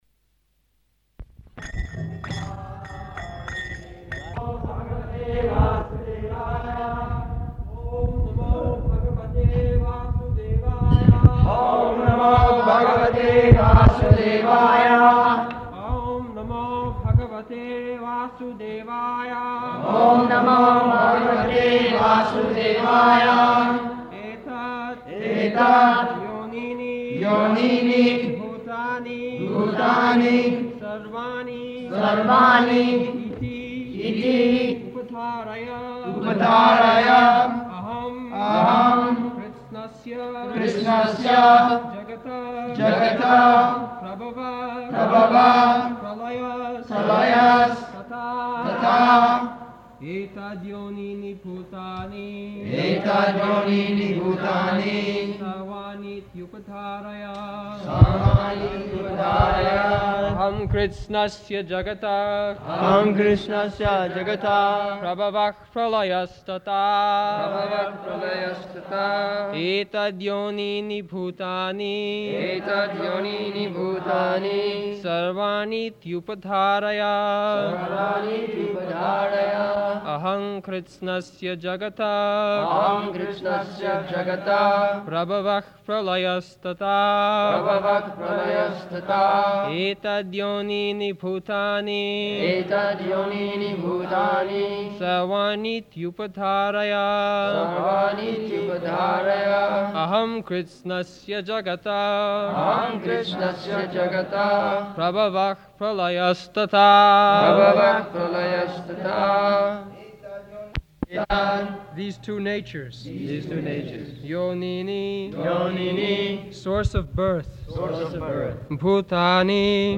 August 12th 1974 Location: Vṛndāvana Audio file